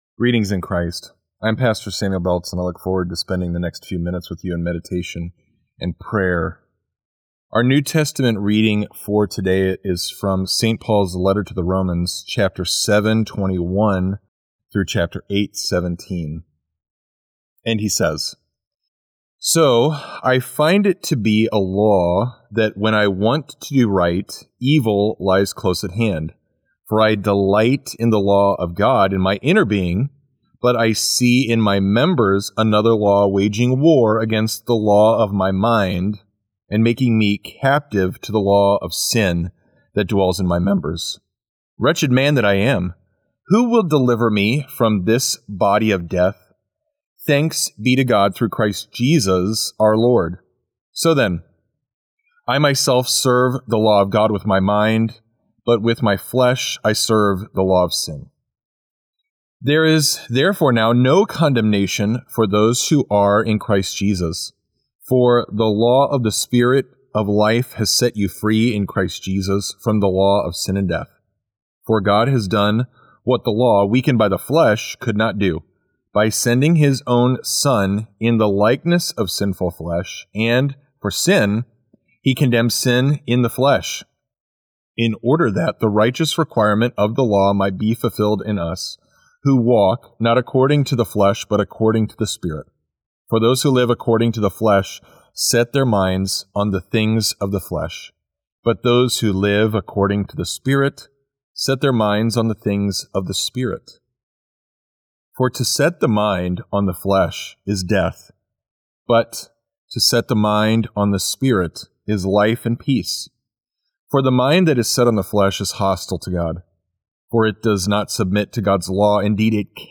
Morning Prayer Sermonette: Romans 7:21-8:17
Hear a guest pastor give a short sermonette based on the day’s Daily Lectionary New Testament text during Morning and Evening Prayer.